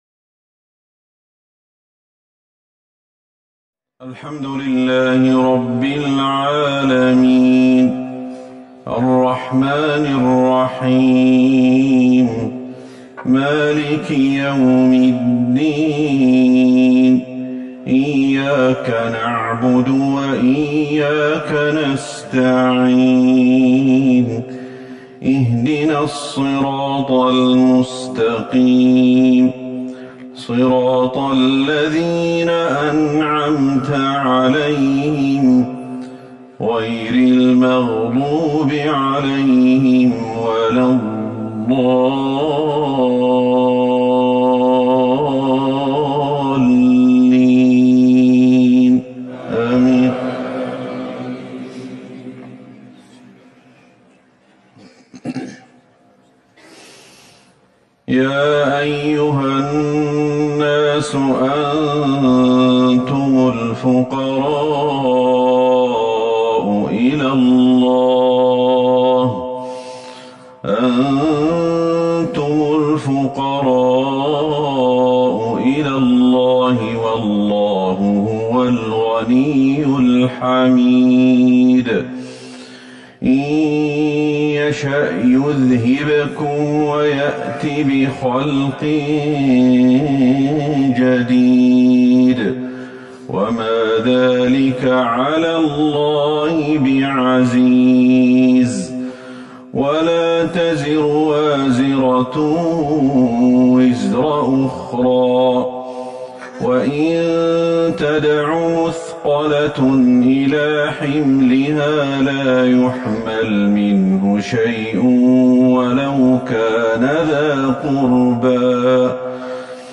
صلاة العشاء ١٧ جمادى الاولى ١٤٤١هـ من سورة فاطر Evening prayer, 5-1-2020 from Surah Fatir > 1441 هـ > الفروض